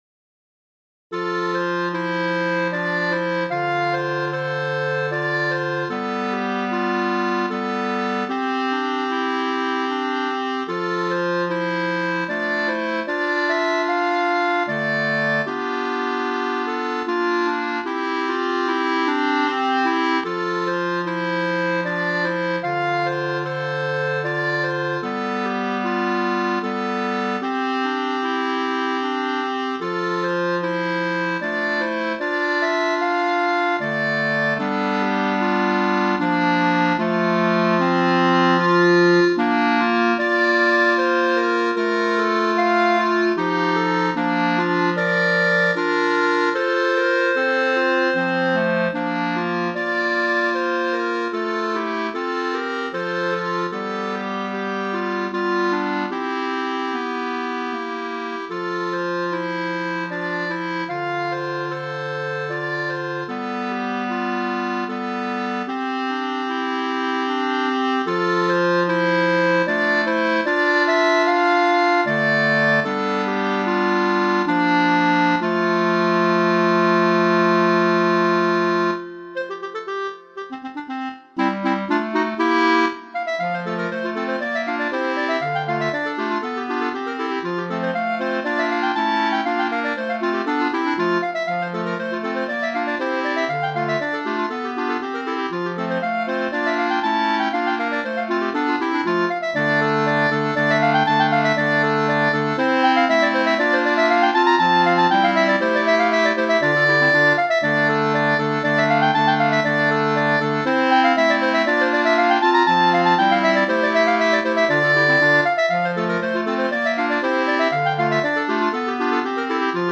4 Clarinettes